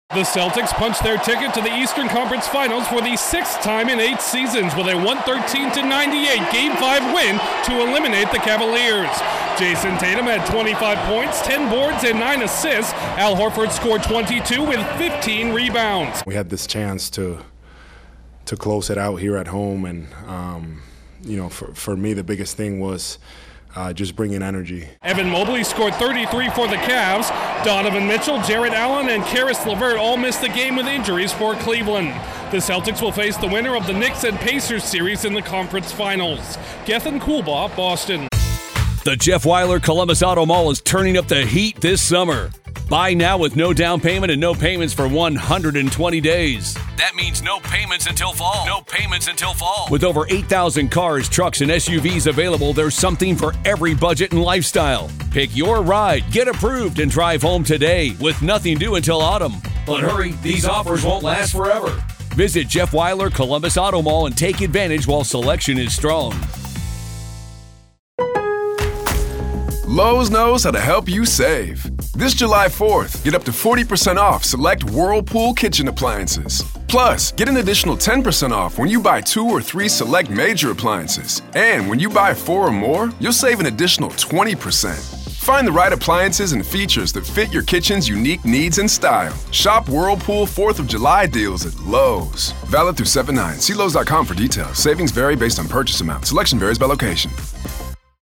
The Celtics are halfway toward an NBA title. Correspondent